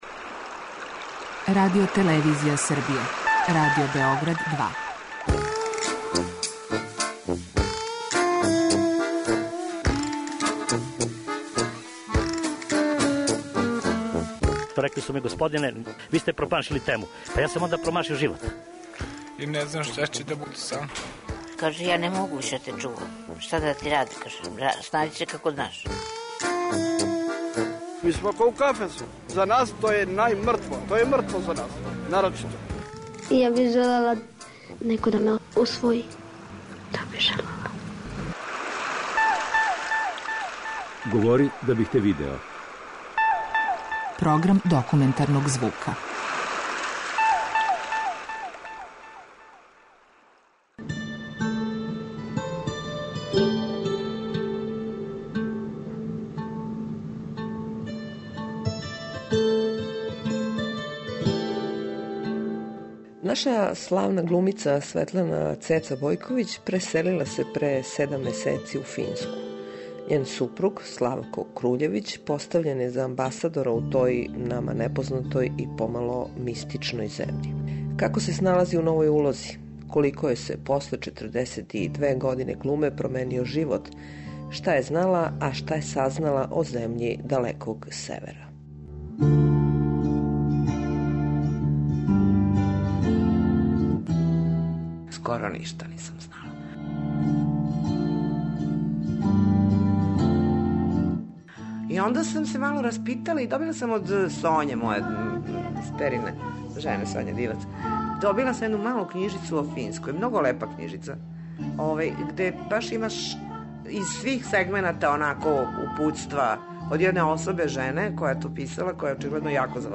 Документарни програм